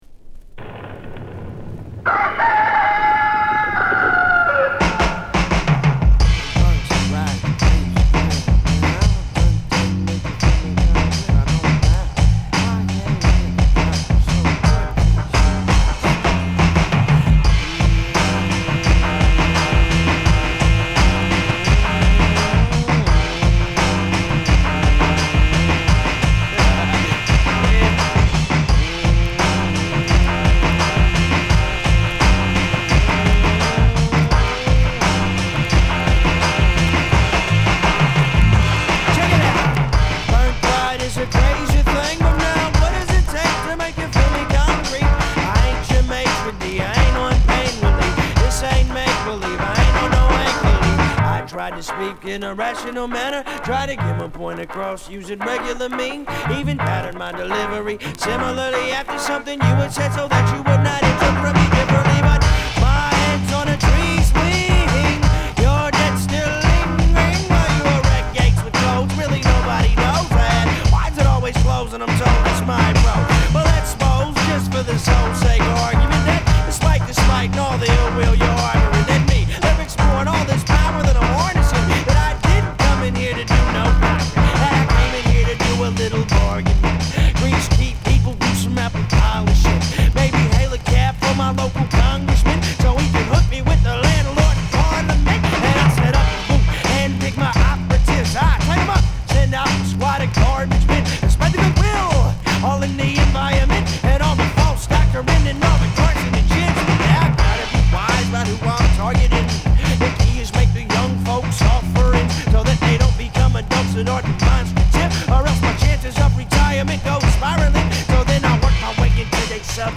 ホーム HIP HOP UNDERGROUND 12' & LP L